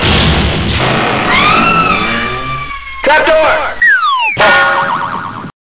Trapdoor.mp3